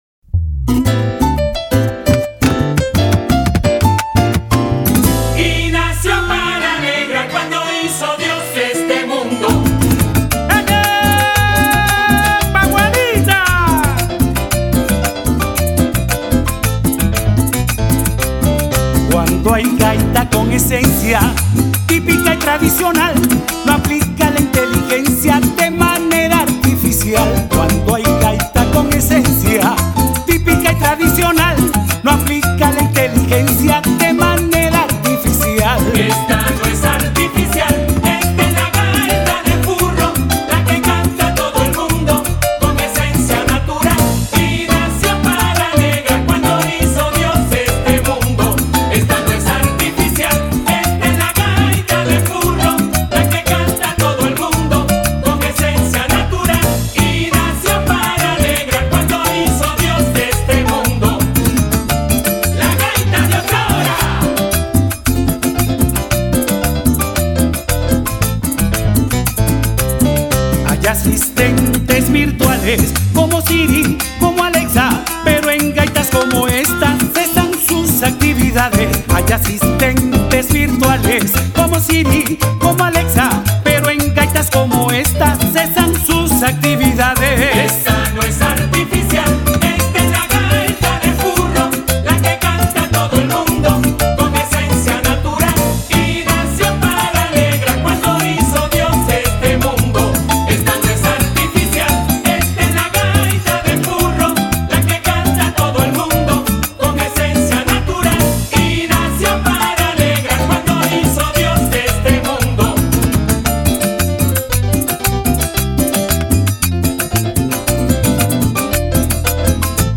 estilo tradicional